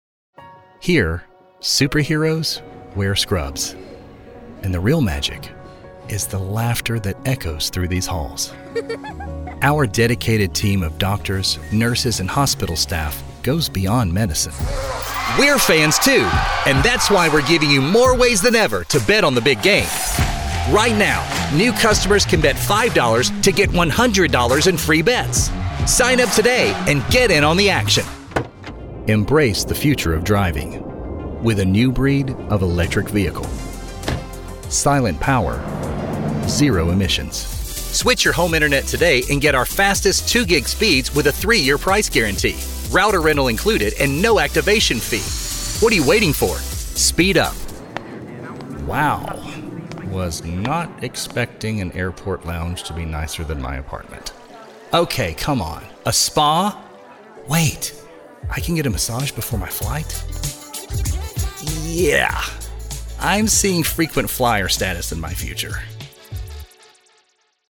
Professional Male Voice Over Talent Worldwide